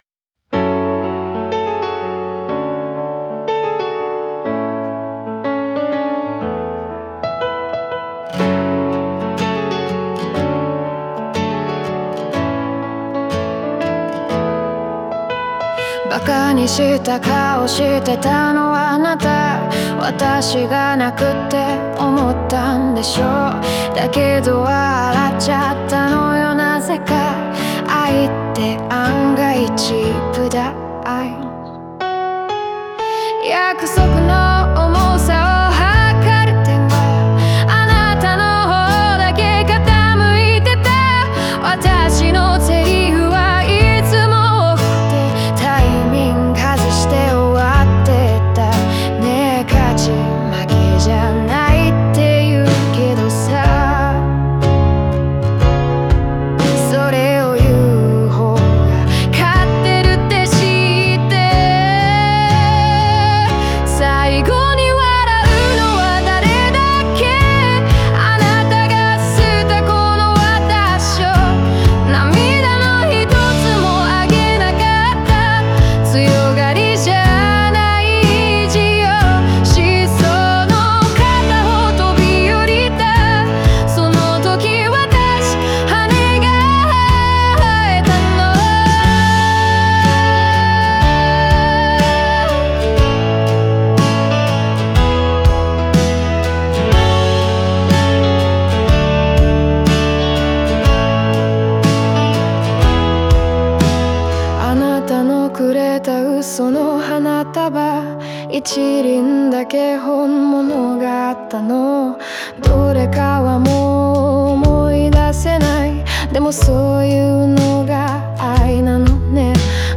オリジナル曲♪
明るい曲調と裏腹に、深く刺さる孤独と再生の物語が、静かな力で心に残る楽曲です。